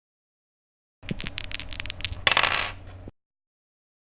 diceroll.mp3